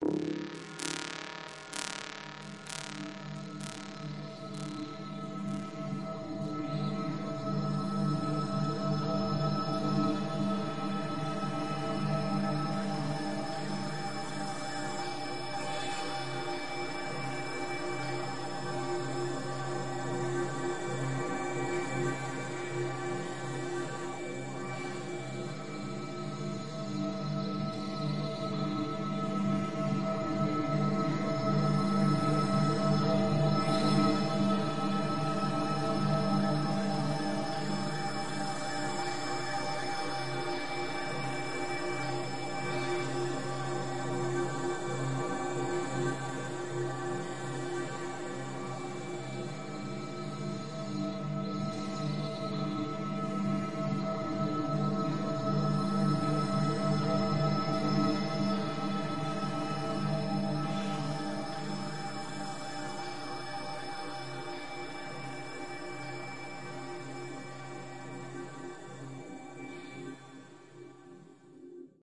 黑暗恐怖氛围铺垫
描述：缓慢演变的铺垫环境音，微妙变化的黑暗恐怖氛围。
标签： 环境音 黑暗 进化 威胁 怪异 铺垫
声道立体声